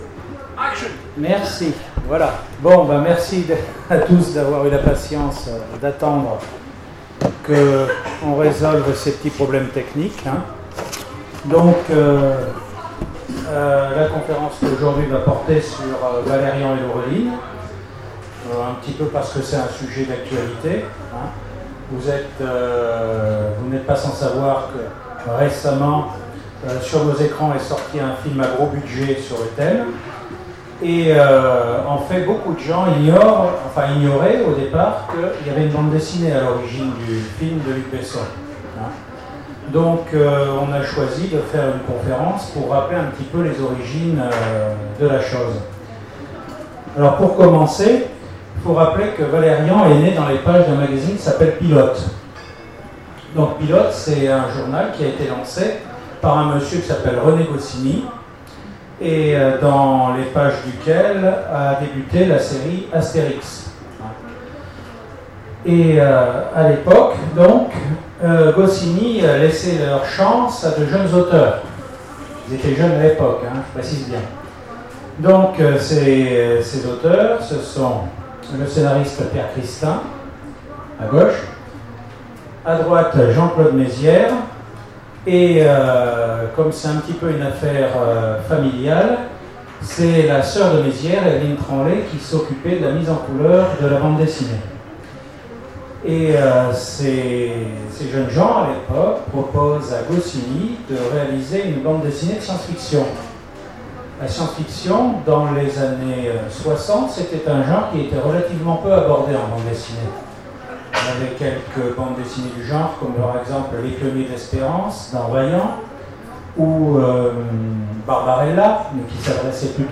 Aventuriales 2017 : Conférence Valerian - ActuSF - Site sur l'actualité de l'imaginaire
Aventuriales_2017_table_ ronde_valerian_ok.mp3